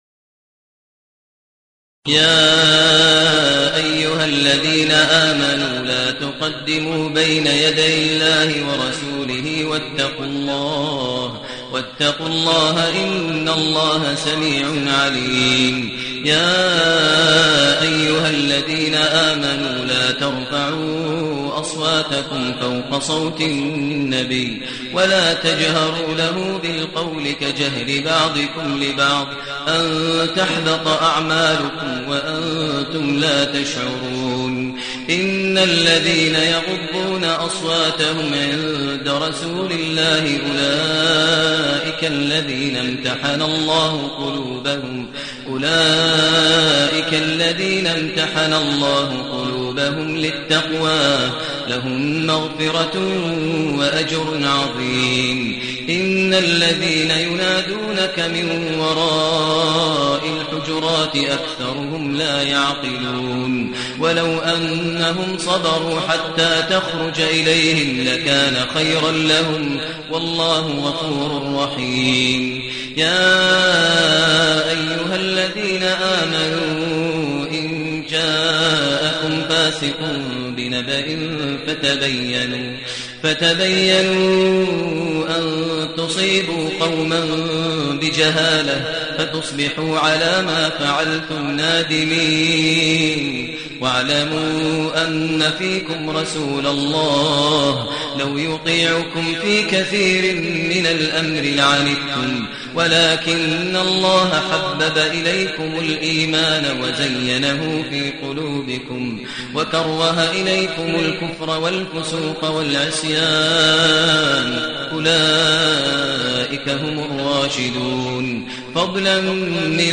المكان: المسجد النبوي الشيخ: فضيلة الشيخ ماهر المعيقلي فضيلة الشيخ ماهر المعيقلي الحجرات